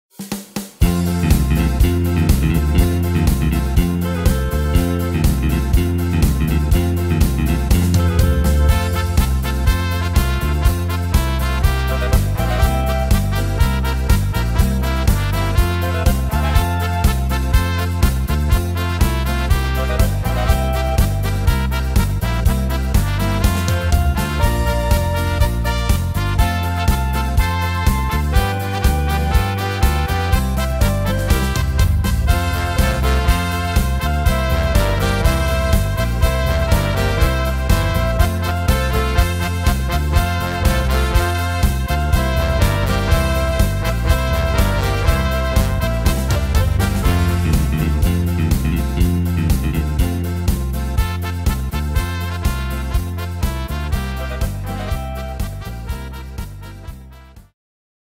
Tempo: 122 / Tonart: F-Dur